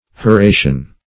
Search Result for " horatian" : The Collaborative International Dictionary of English v.0.48: Horatian \Ho*ra"tian\, a. Of or pertaining to Horace, the Latin poet, or resembling his style.
horatian.mp3